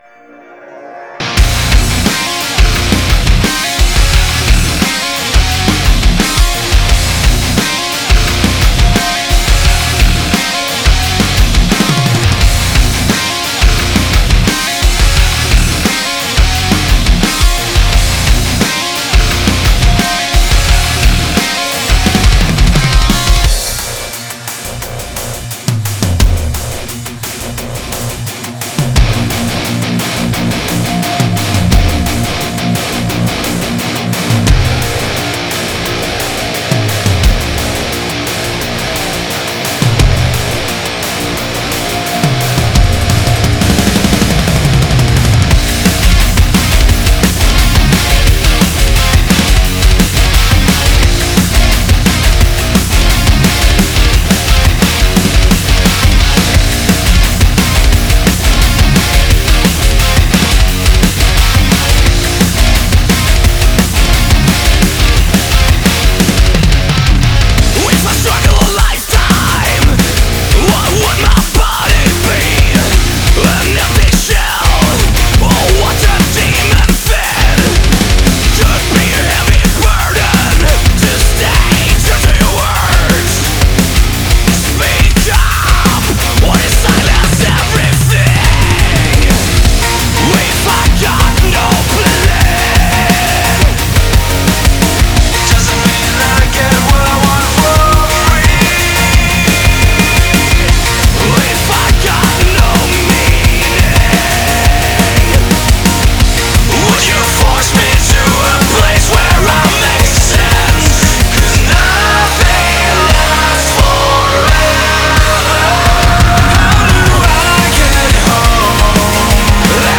| Категория: Рок, Альтернатива | Теги: | Рейтинг: 0.0 |